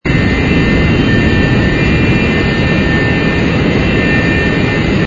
engine_li_cruise_loop.wav